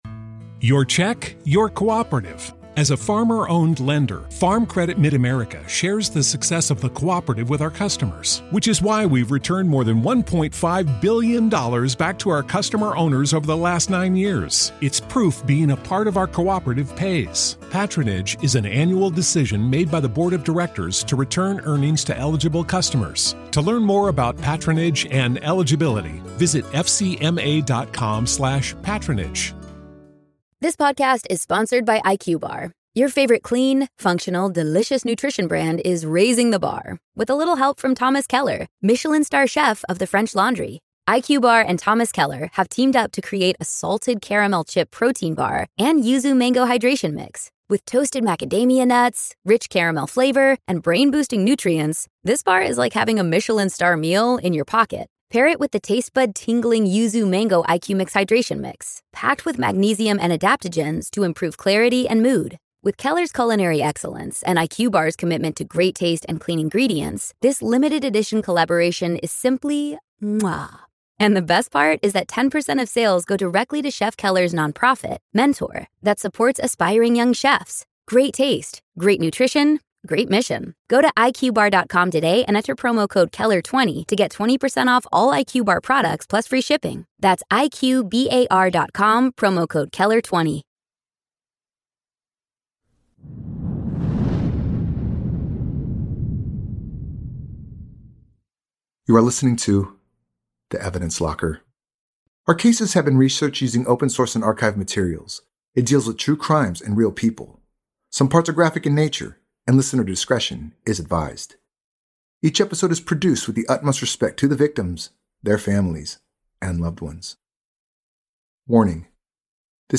True Crime, Society & Culture, Documentary, History